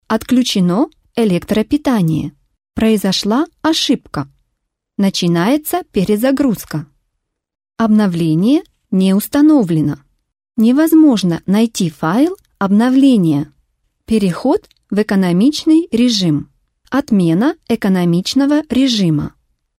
Voice actor sample
외국어성우
차분/편안